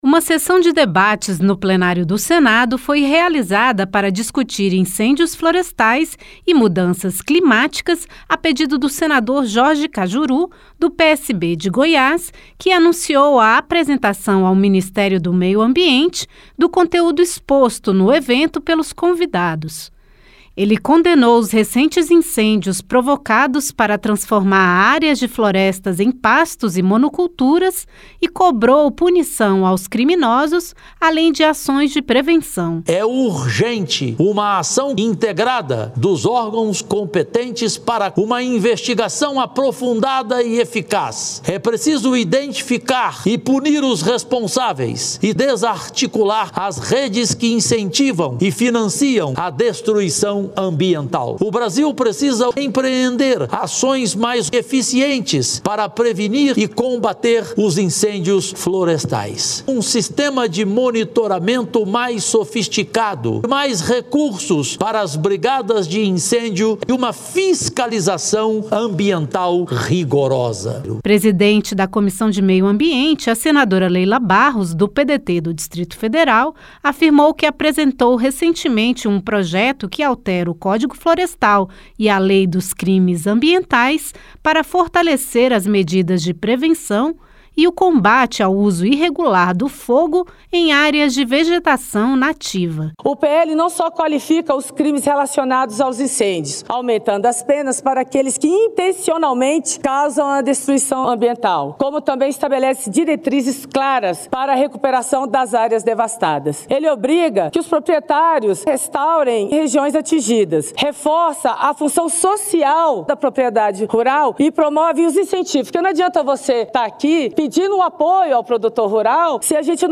O Senado promoveu nesta quarta-feira (25) uma sessão de debates sobre os incêndios e as mudanças climáticas que afetam o país. O senador senador Jorge Kajuru (PSB-GO), que conduziu a sessão, defendeu a investigação e a punição de quem provoca queimadas.